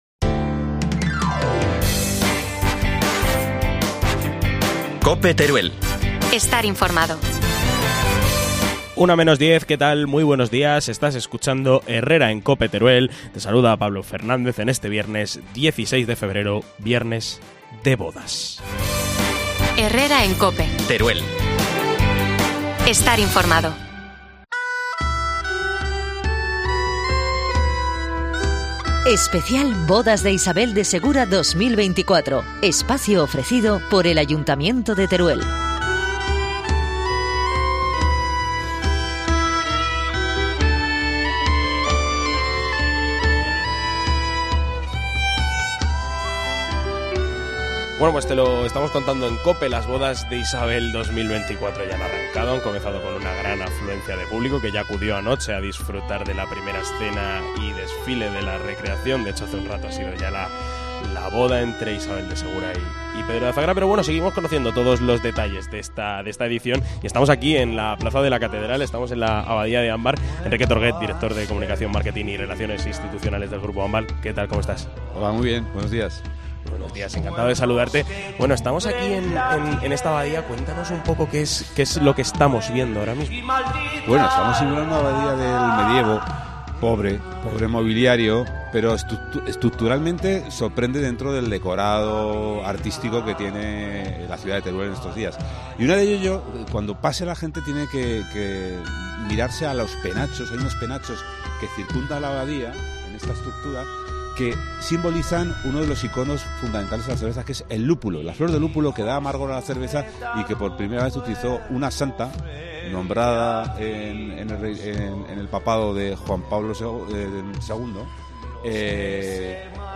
AUDIO: Especial Herrera en COPE Teruel desde la Plaza de la Catedral